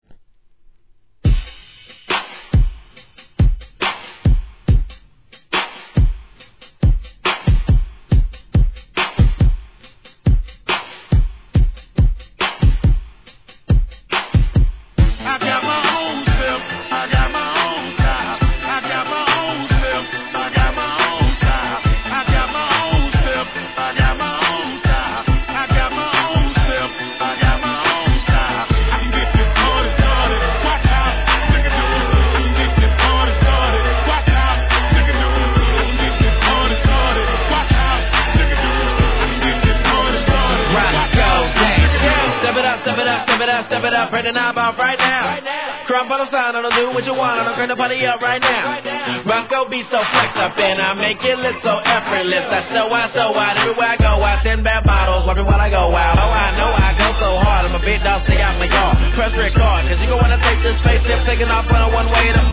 HIP HOP/R&B
BPM69